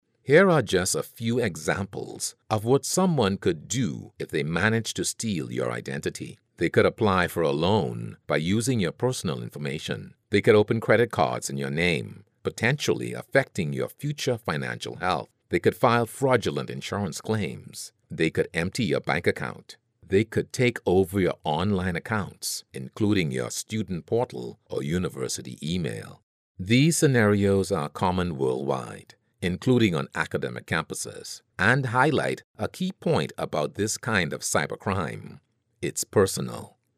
Englisch (Karibik)
Erklärvideos
Neumann U87
BaritonBassNiedrig
VertrauenswürdigUnternehmenAutorisierendSamtig